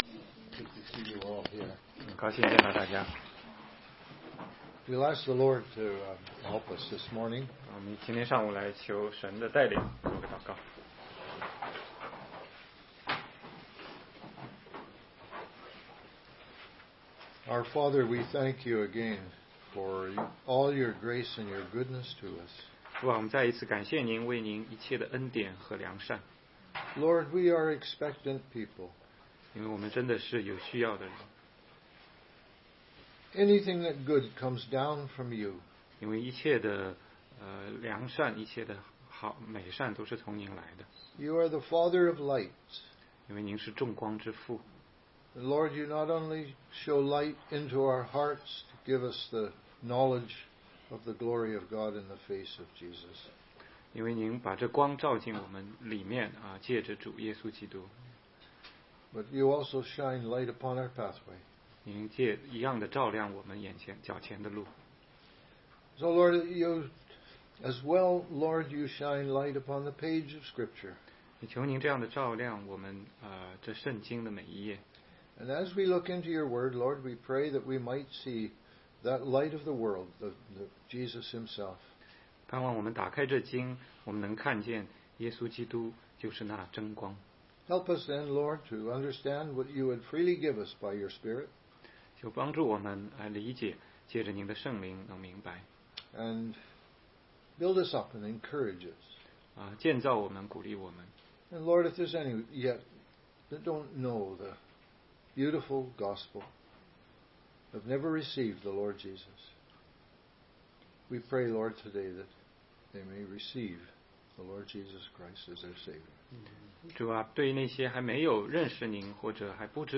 16街讲道录音 - 怎样才能读懂圣经系列之九